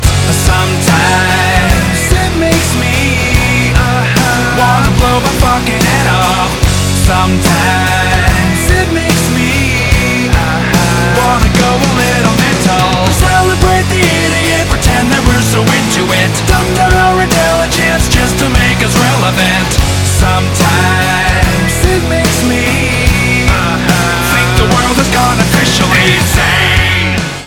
• Качество: 192, Stereo
Веселая и заводная песенка